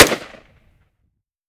fps_project_1/5.56 M4 Rifle - Gunshot A 003.wav at d65e362539b3b7cbf77d2486b850faf568161f77 - fps_project_1 - Gitea: Git with a cup of tea